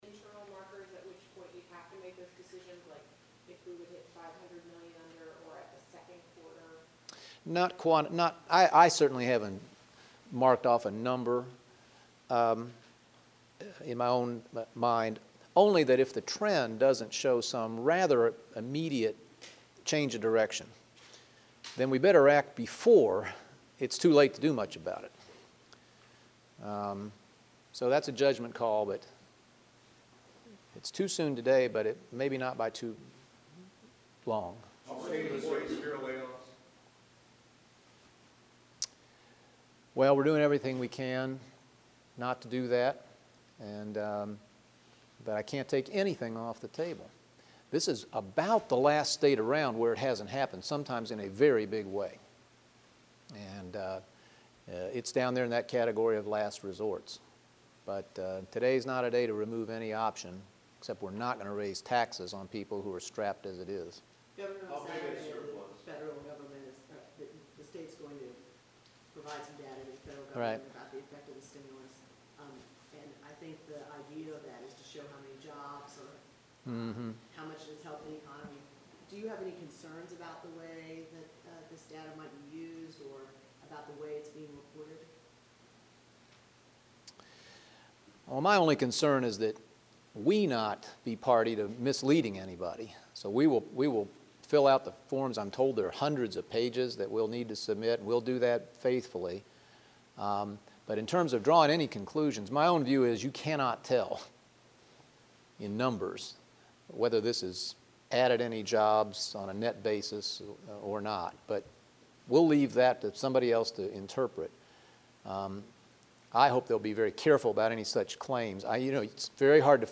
You can hear the Governor’s comments to the media below.